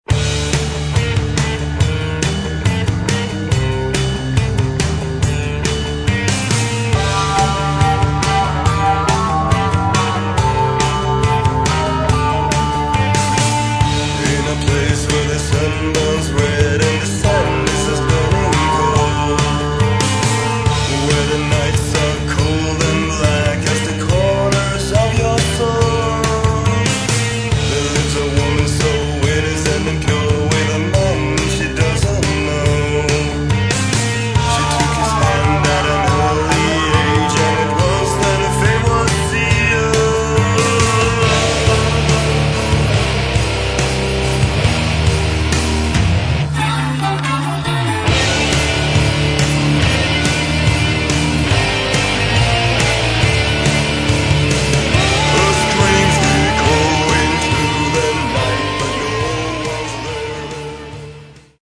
Второй полноформатный альбом шведской готической группы.
вокал, акустическая гитара
гитара
бас
клавиши
барабаны
гармоника
женский вокал